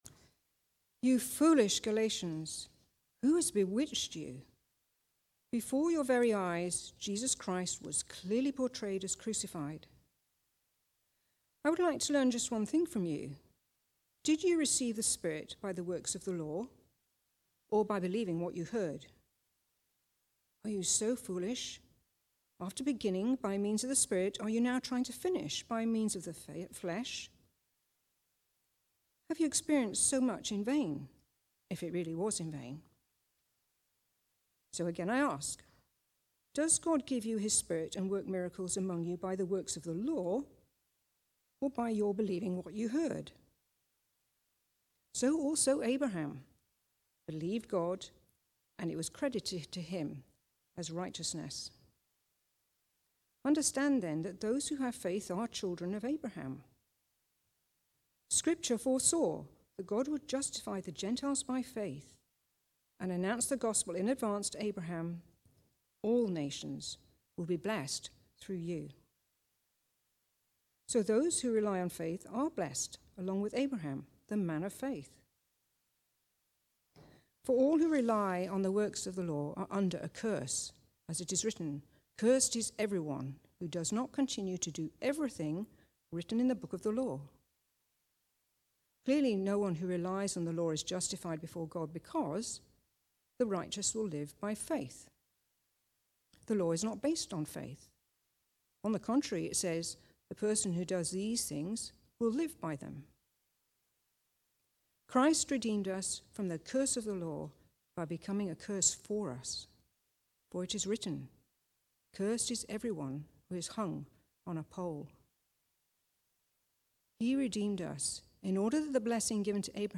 Preaching
Recorded at Woodstock Road Baptist Church on 29 September 2024.